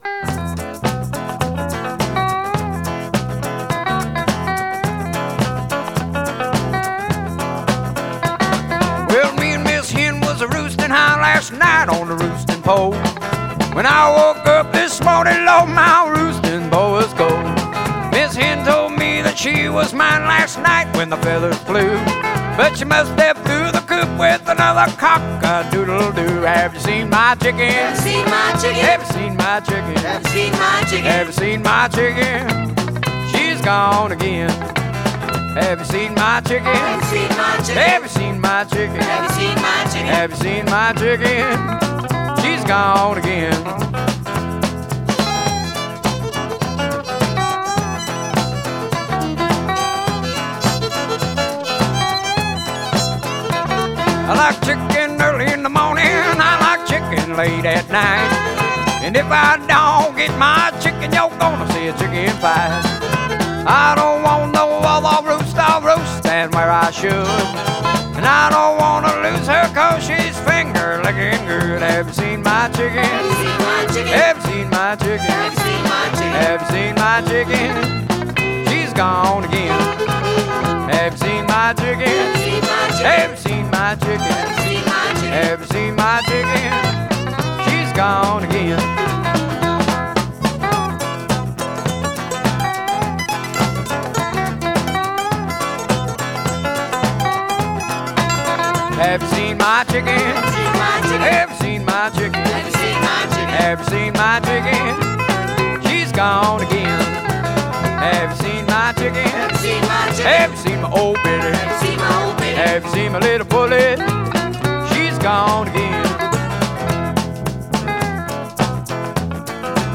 Rough Cuts- Jamming
Guitar
Vocals, Sax, Keyboards
Drums
Bass, Guitar